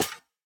Minecraft Version Minecraft Version 25w18a Latest Release | Latest Snapshot 25w18a / assets / minecraft / sounds / block / spawner / step4.ogg Compare With Compare With Latest Release | Latest Snapshot
step4.ogg